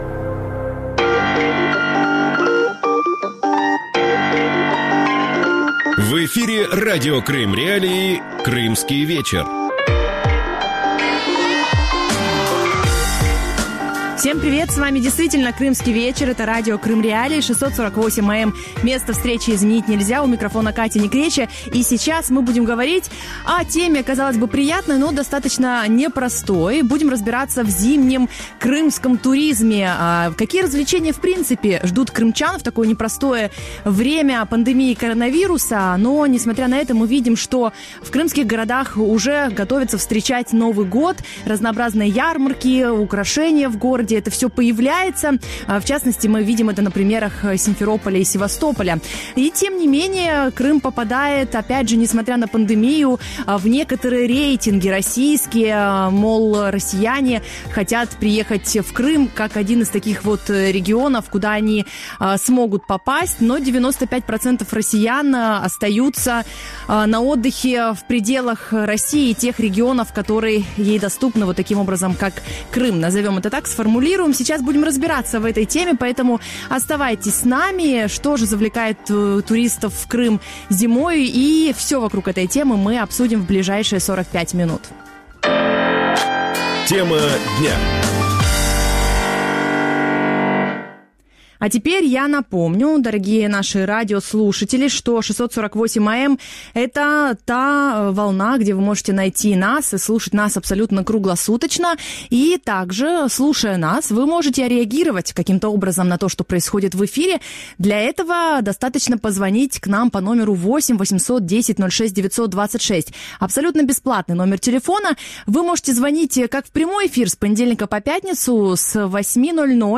Эти и другие актуальные темы в студии Радио Крым.Реалии в ток-шоу «Крымский вечер»